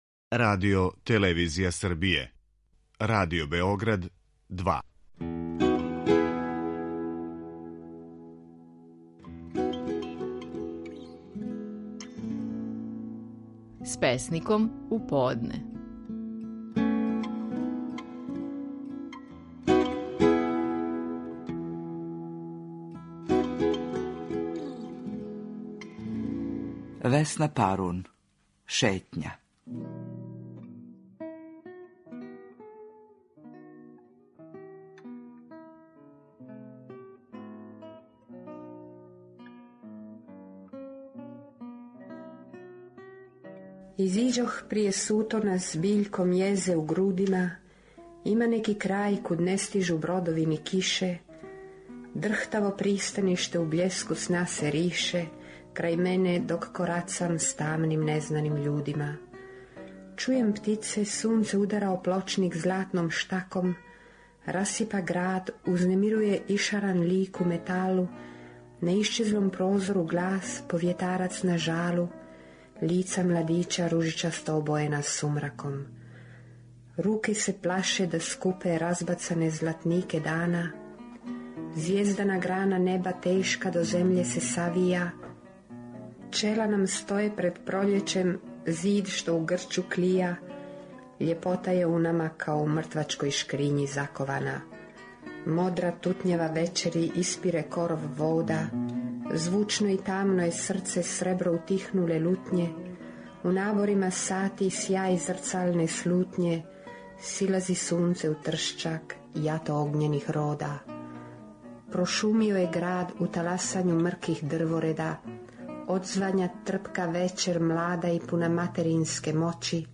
Стихови наших најпознатијих песника, у интерпретацији аутора.
Весна Парун говори песму „Шетња".